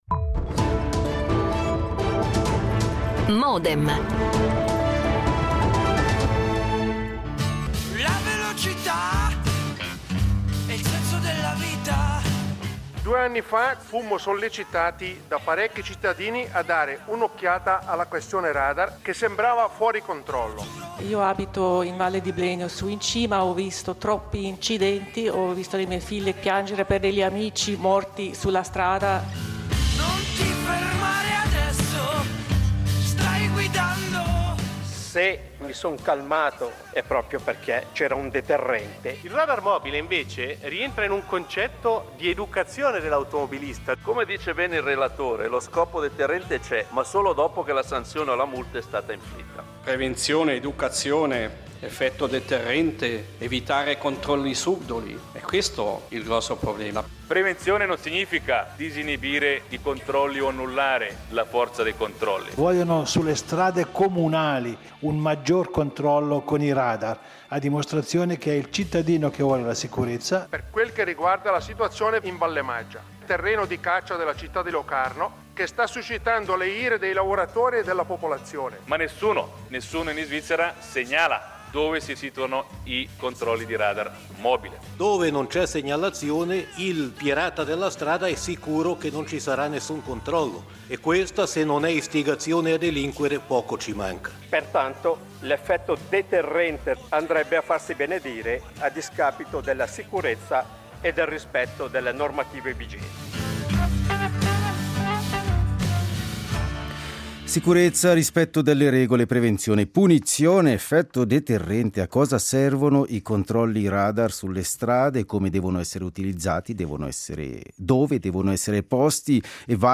A Modem ne dibattono: i granconsiglieri, Fabio Badasci (Lega) e Giorgio Galusero (PLRT)